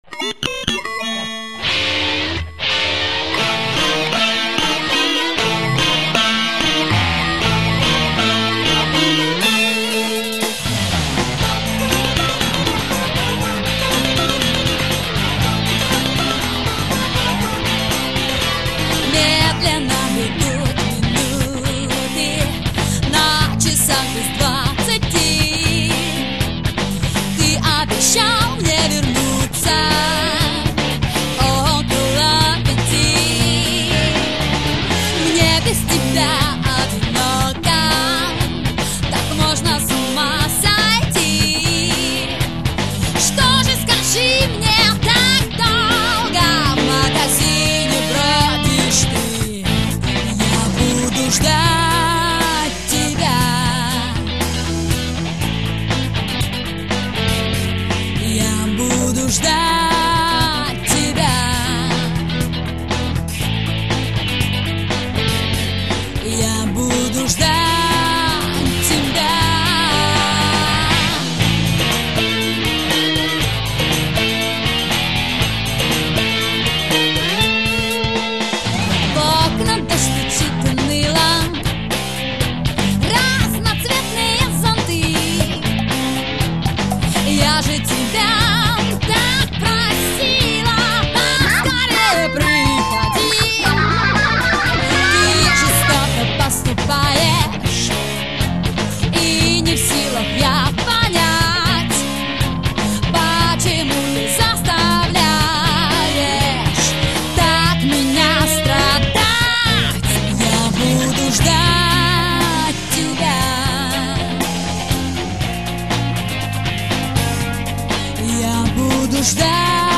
Поп-рок, можно вдоволь поорать! Для женского вокала)